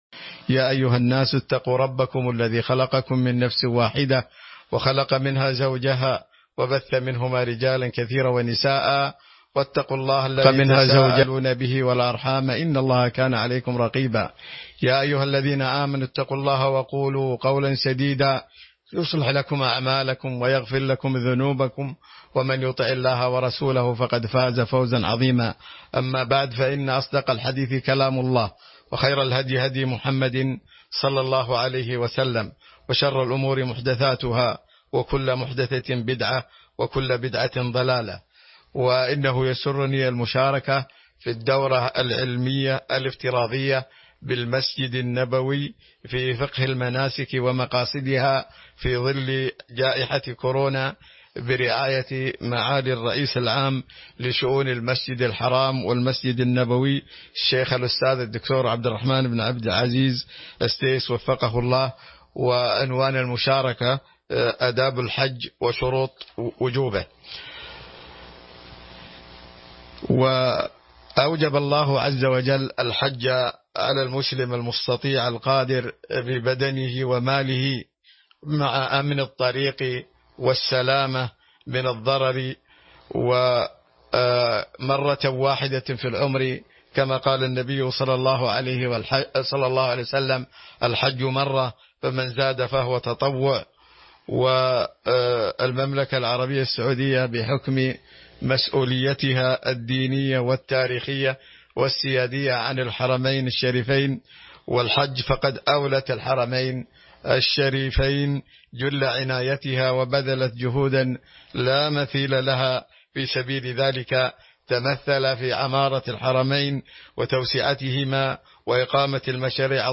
تاريخ النشر ٢ ذو الحجة ١٤٤١ هـ المكان: المسجد النبوي الشيخ